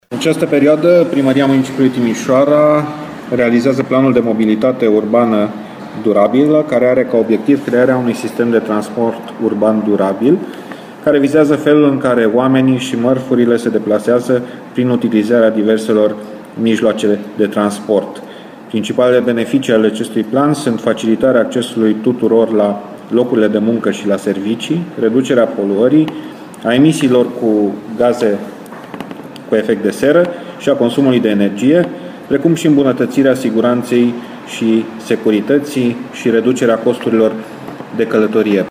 Potrivit viceprimarului Dan Diaconu, planul e unul cu bătaie lungă. Cercetarea va stabili modul în care se va dezvolta infrastructura rutieră din zona Timișoarei în următorii 15 ani: